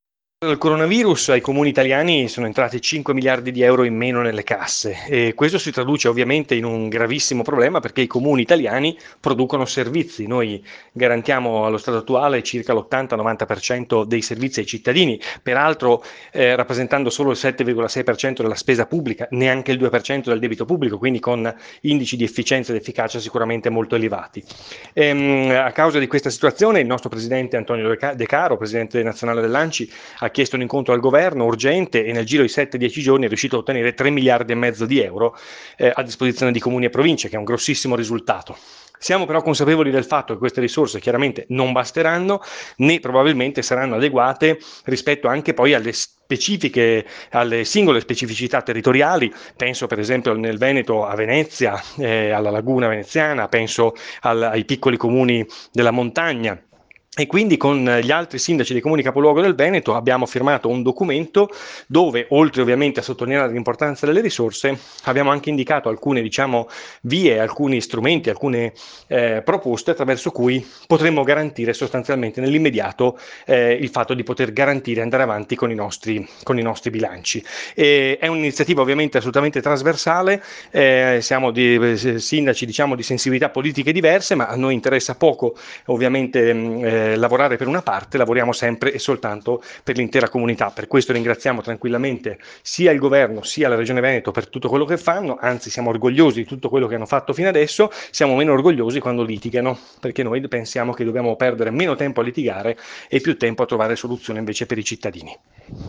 AI MICROFONI DI RADIO PIU’ IL SINDACO DI BELLUNO JACOPO MASSARO